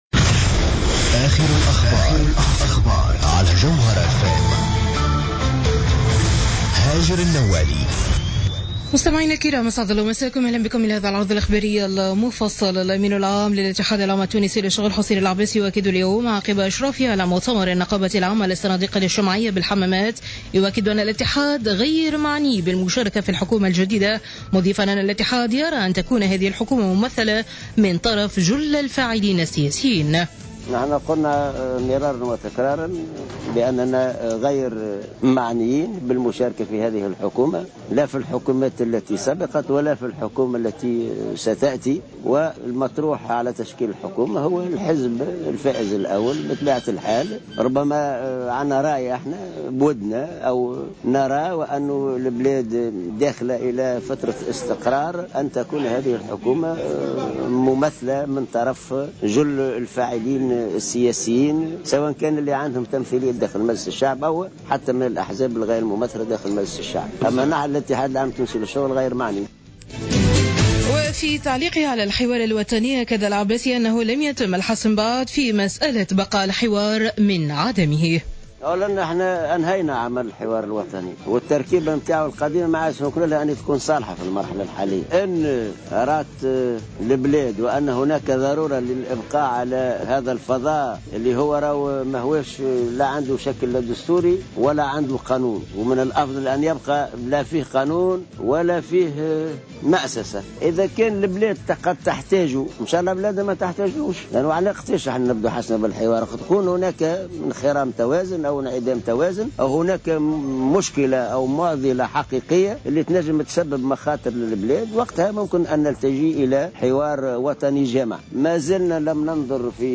نشرة أخبار السابعة مساء ليوم السبت 27-12-14